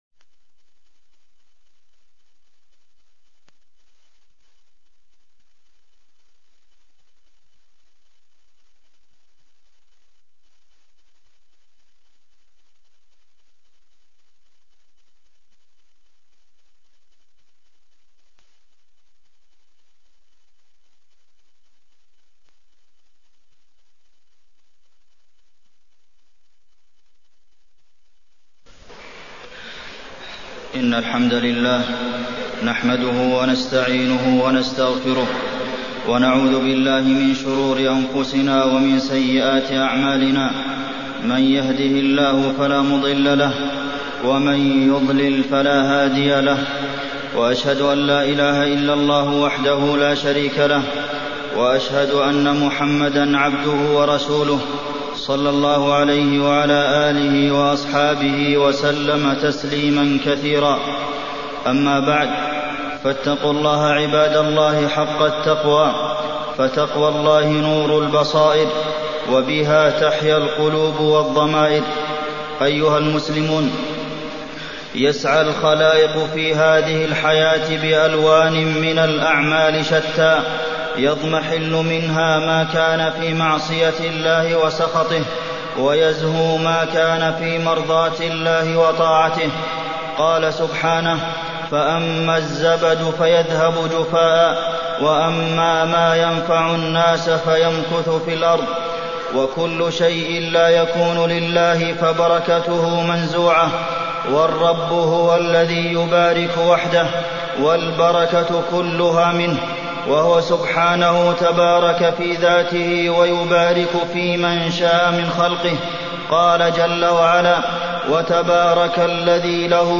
تاريخ النشر ١٤ محرم ١٤٢٥ هـ المكان: المسجد النبوي الشيخ: فضيلة الشيخ د. عبدالمحسن بن محمد القاسم فضيلة الشيخ د. عبدالمحسن بن محمد القاسم أثر البركة على المسلم